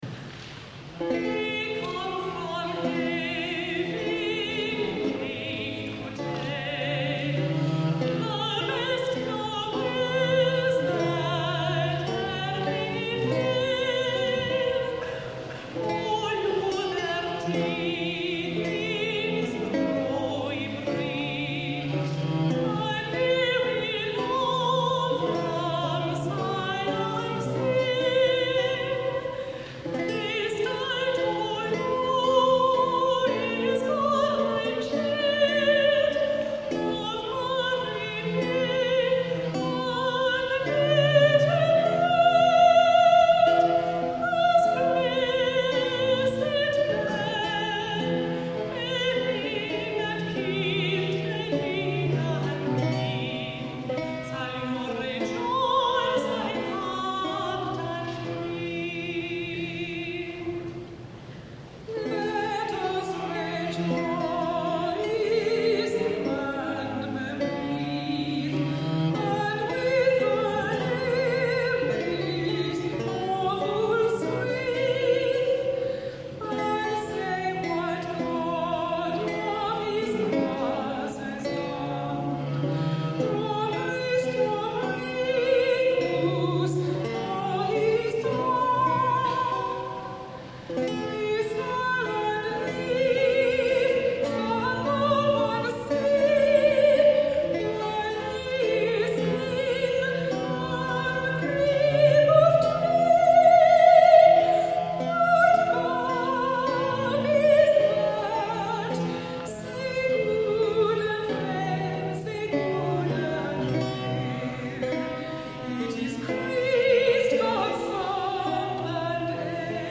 soprano
Lute
viol